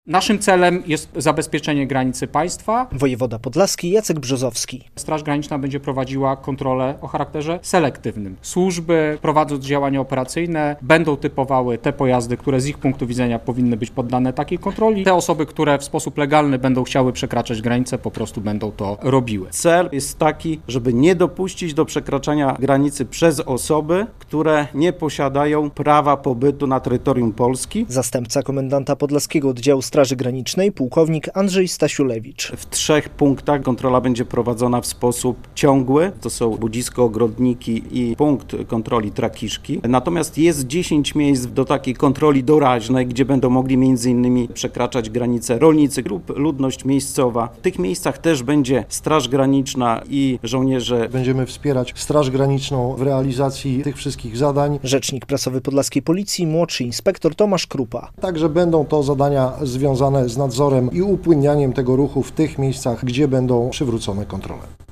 Polska wprowadza kontrole na granicy polsko-litewskiej - konferencja prasowa, 2.07.2025, fot.
relacja